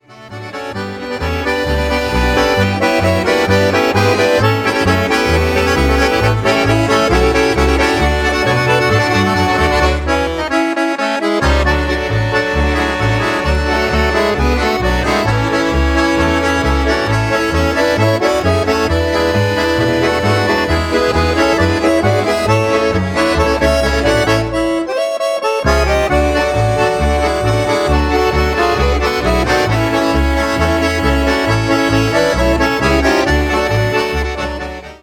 Schnellpolka